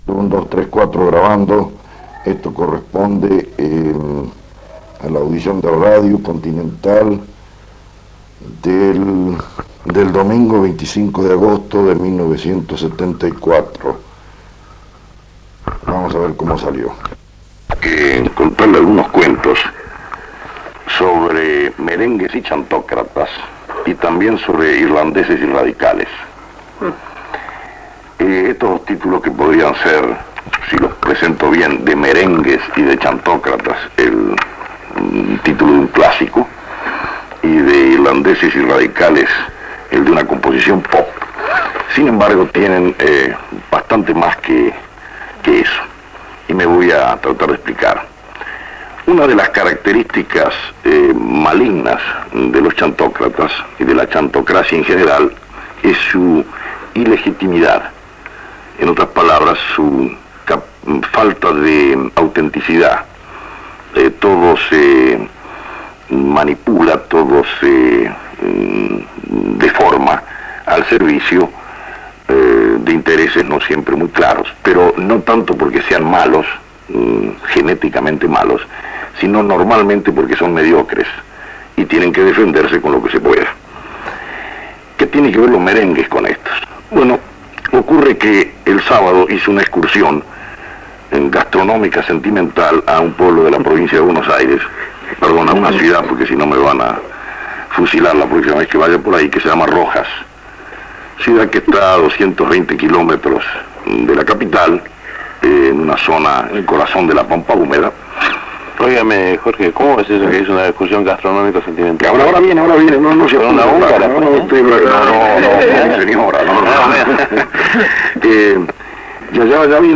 El 25 de Agosto de 1974 Miguel Brascó entrevista a Jorge A. Sabato en su programa "Las 12 horas de la radio" trasmitido por Radio Continental.